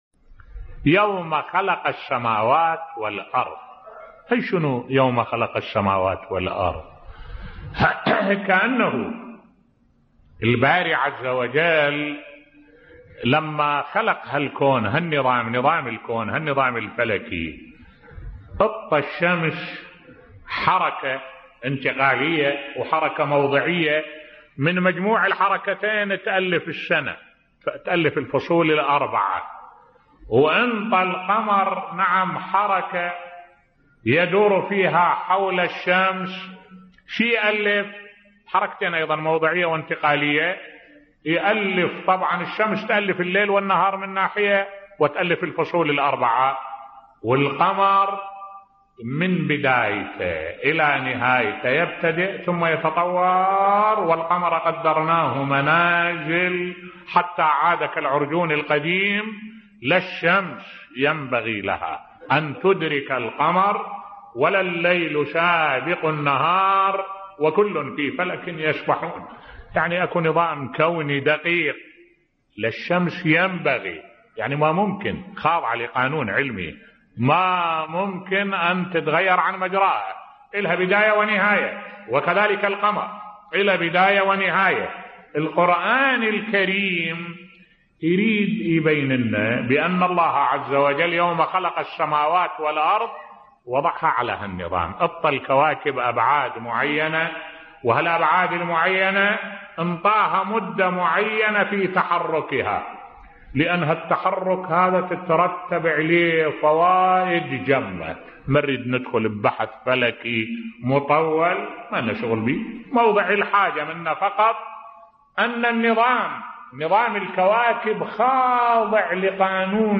ملف صوتی الابداع في نظام الكون بصوت الشيخ الدكتور أحمد الوائلي